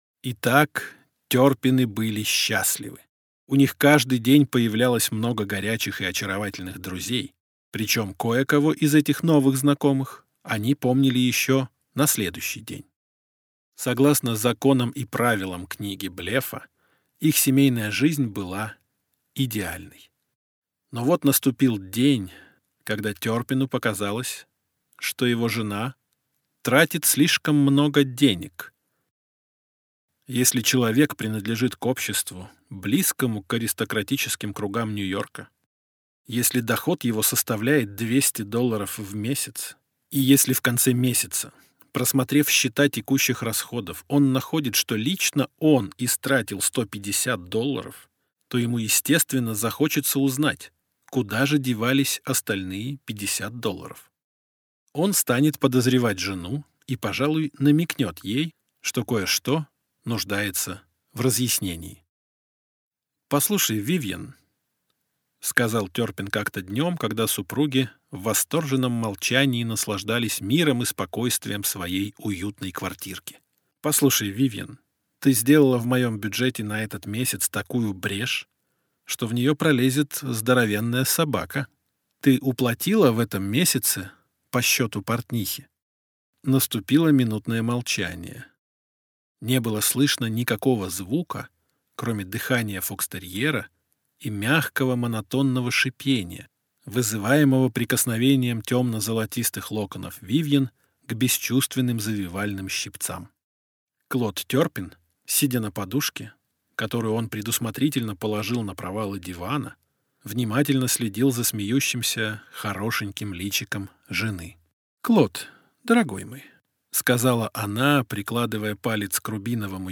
Аудиокнига Чёрствые булки | Библиотека аудиокниг